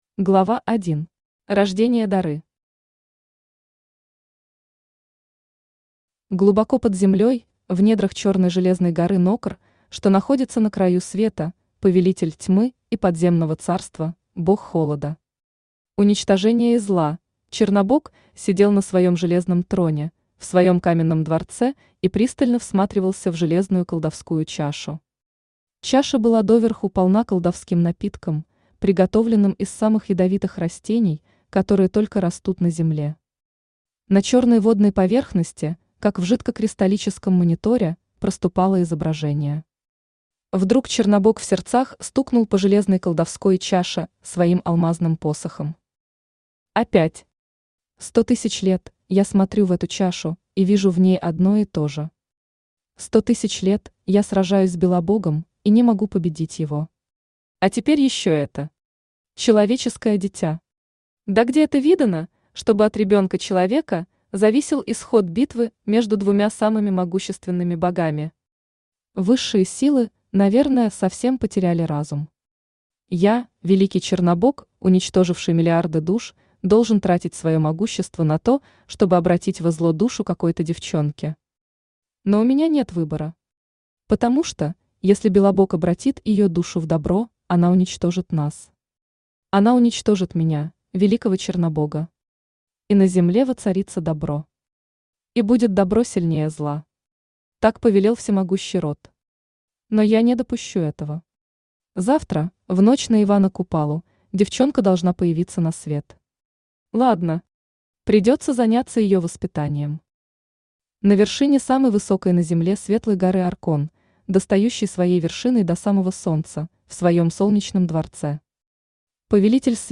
Книга I. По велению Рода Автор Елена Атай Читает аудиокнигу Авточтец ЛитРес.